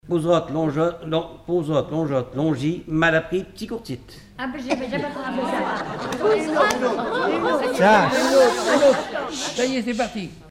Mémoires et Patrimoines vivants - RaddO est une base de données d'archives iconographiques et sonores.
formulette enfantine : jeu des doigts
Collectif-veillée (1ère prise de son)
Pièce musicale inédite